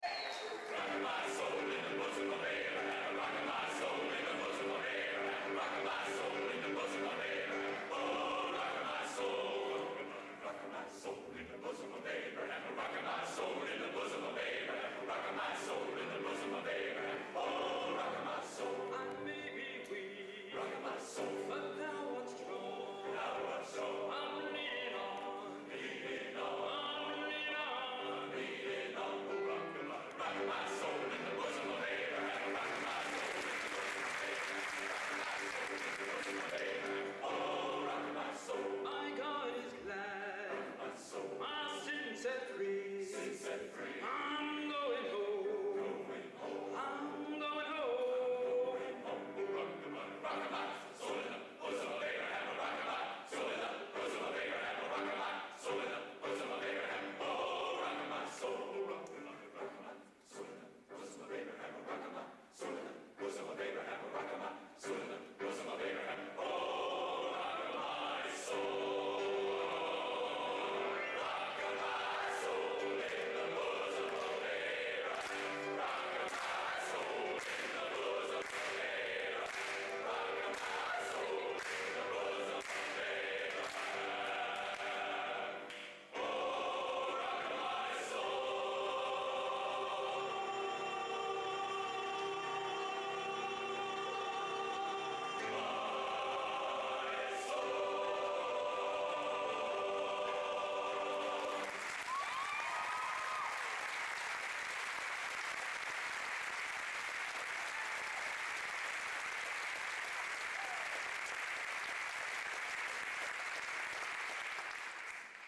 Location: Purdue Memorial Union, West Lafayette, Indiana
Genre: Spiritual | Type: